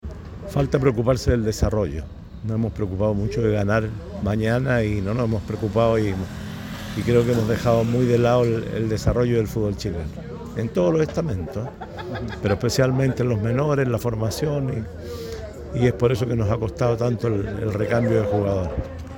En diálogo con ADN Deportes, el expresidente de la ANFP manifestó su preocupación sobre el actual momento del balompié nacional.